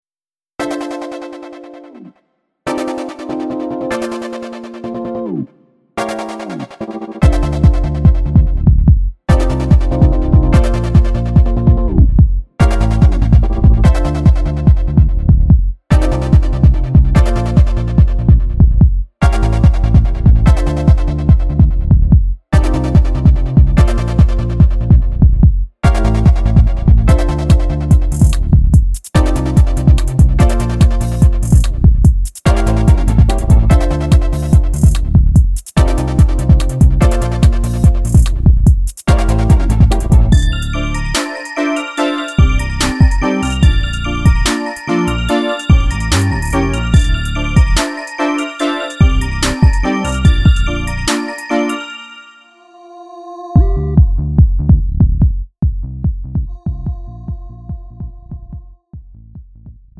MR 가수
음정 -1키
장르 가요